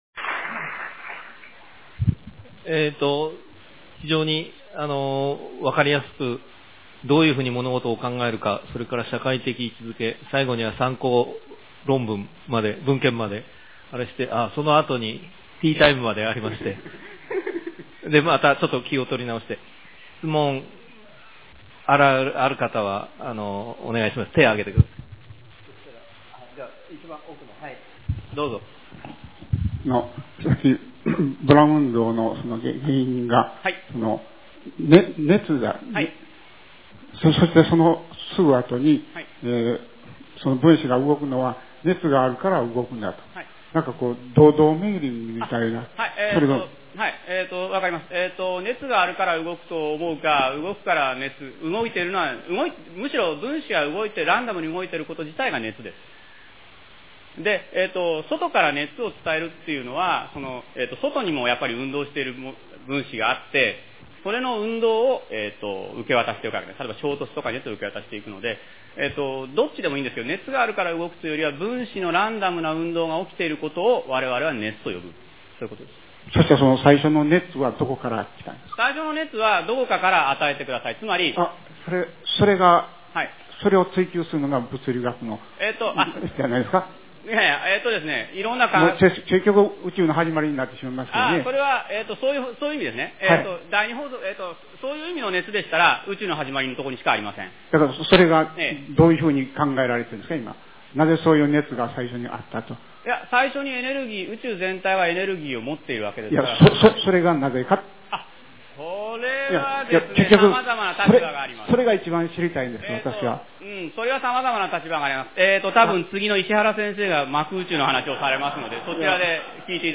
講演会の記録
質問　QT(13MB)　 WM(8.0MB)